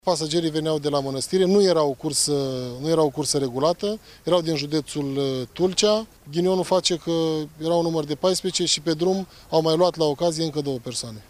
Prefectul judeţului, Eduard Popica:
7-oct-rdj-18-Eduard-Popica.mp3